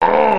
A sound used for when an enemy is defeated in Donkey Kong Country
Defeat_sound.oga.mp3